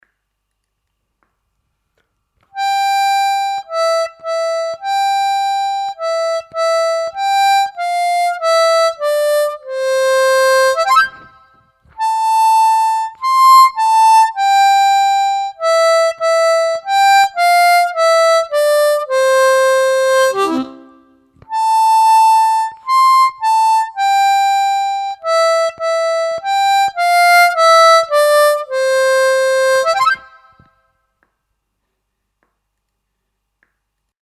Слайд (глиссандо) и дроп-офф на губной гармошке
Сыграть мелодию «Маленькой ёлочке холодно зимой»,  используя технику дроп-офф.
malenkoj-jolochke-s-dropoff.mp3